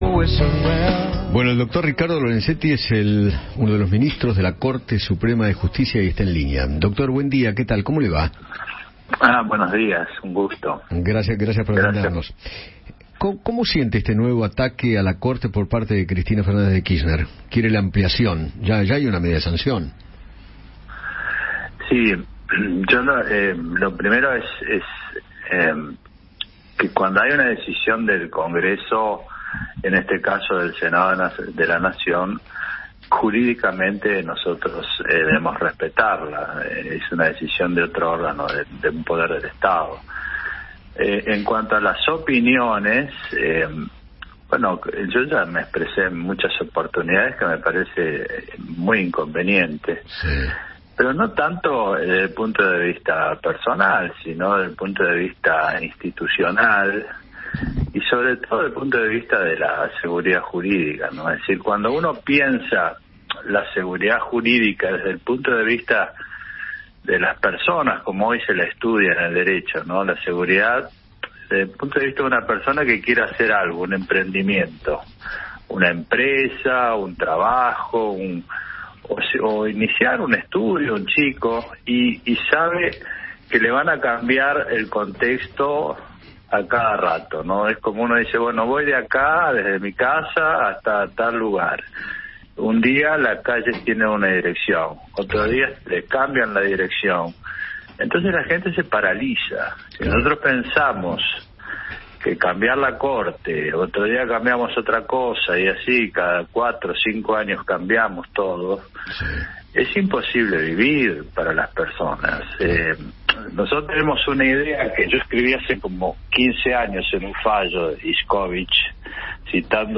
Ricardo Lorenzetti, juez de la Corte Suprema de Justicia de la Nación Argentina, conversó con Eduardo Feinmann sobre el nuevo ataque de Cristina Kirchner a la Corte Suprema de Justicia.